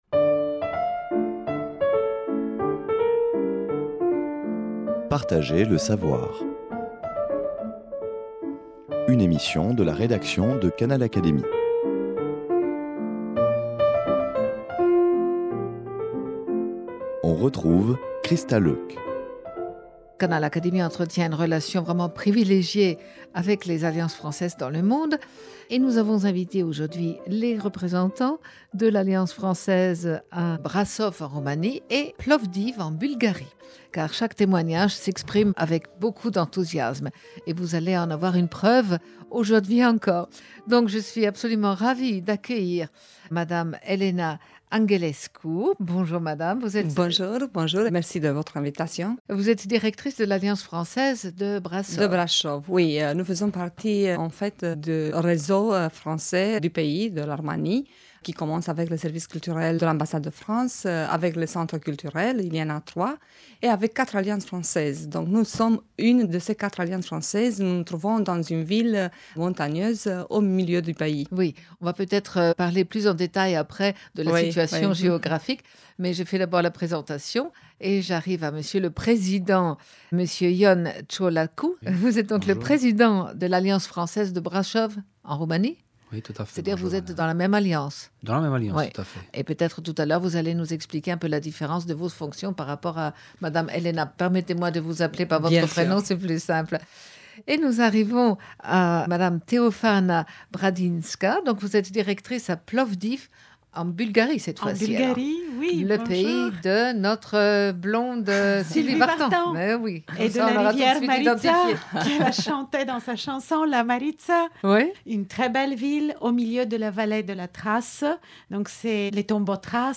Les représentants des Alliances Françaises en Roumanie et en Bulgarie ont fait le déplacement jusque dans nos studios parisiens pour nous faire vivre leur expérience dans leurs pays respectifs. La francophonie est à l’honneur dans les pays de l’Est !